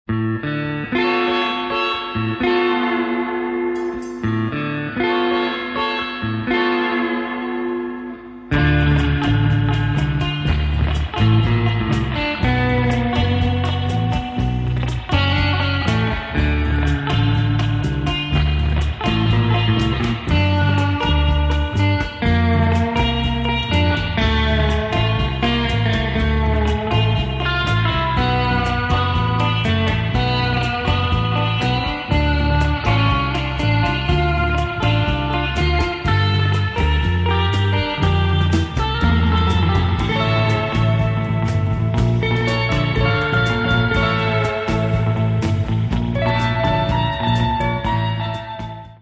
relaxed slow instr.